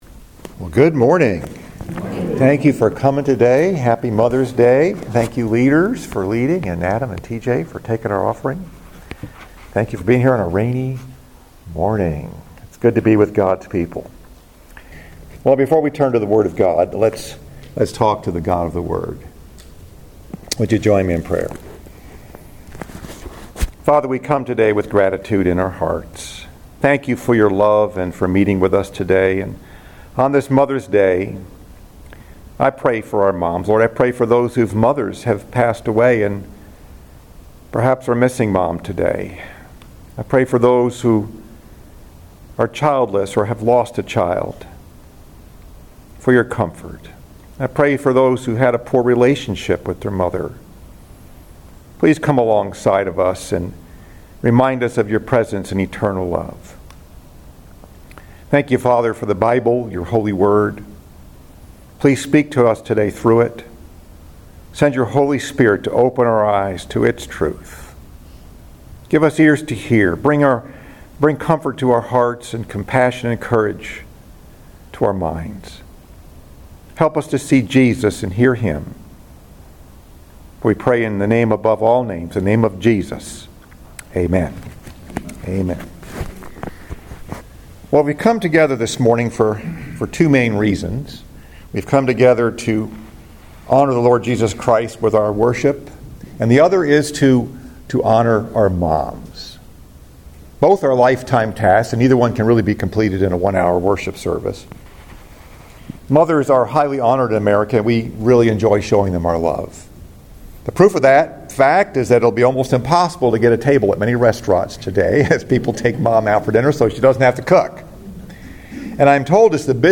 Message: “The Best Mom Ever” Scripture: Luke 1:26-38 Mother’s Day